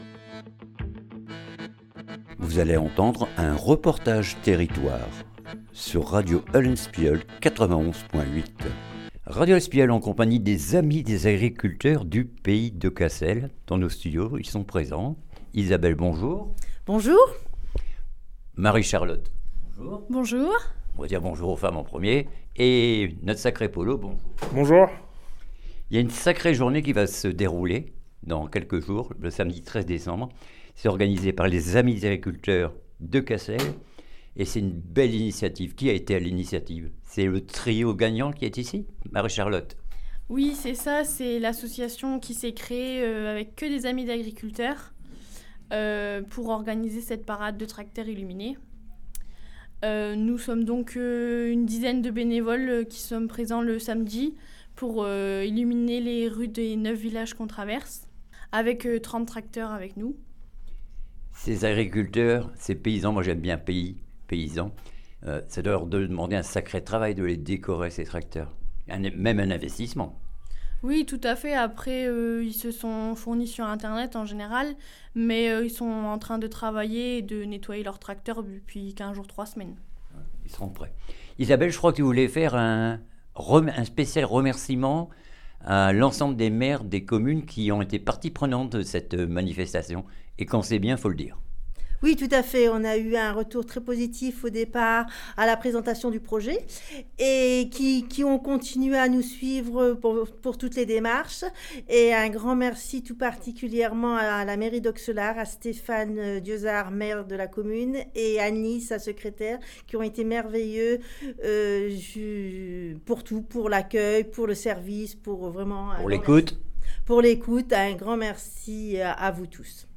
REPORTAGE TERRITOIRE LES AMIS DES AGRICULTEURS DU PAYS DE CASSEL